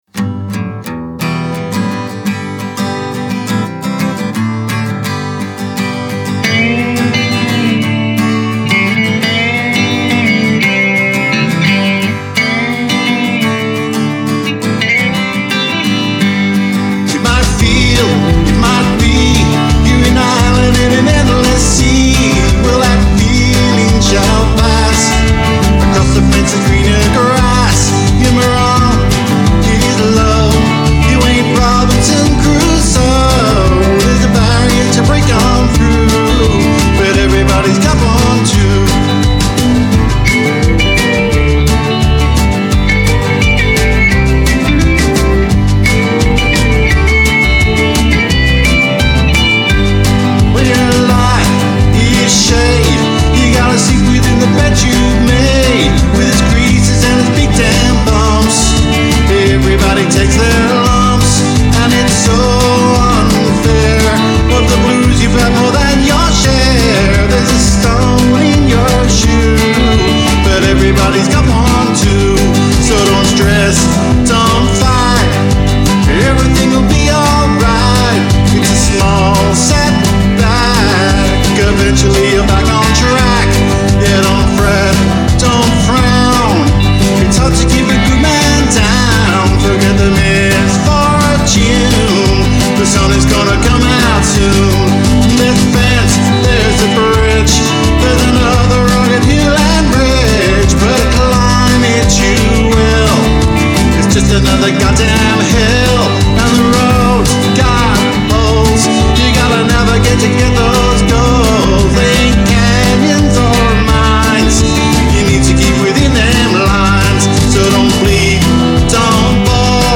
the rocking Aussie outfit
buoyant take on a sometimes sad style of music
toe-tapping tune accentuates the positive